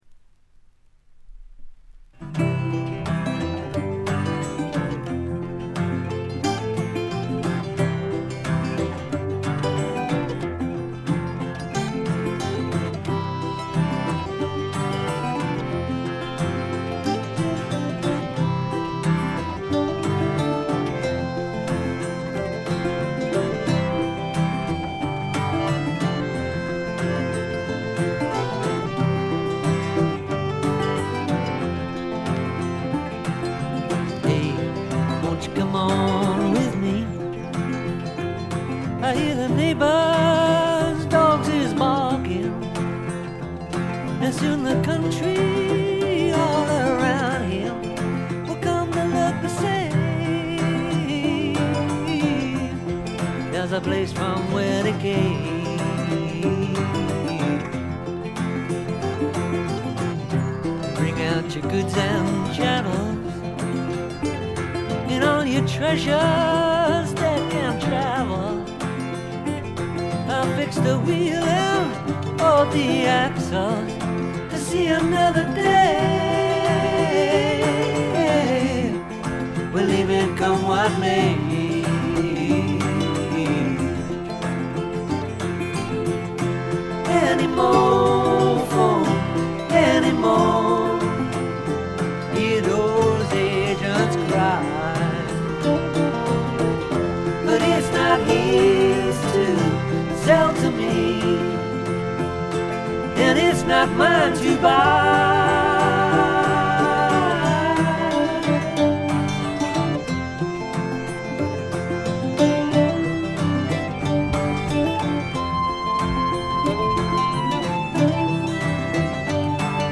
ほとんどノイズ感無し。
試聴曲は現品からの取り込み音源です。
Mixed at IBC.